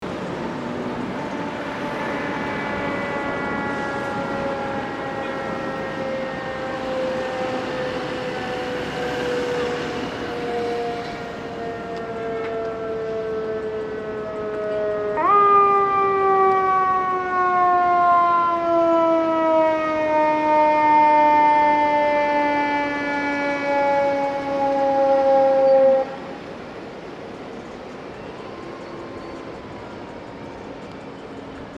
Download Free Fire Truck sound effect for free.
Free Fire Truck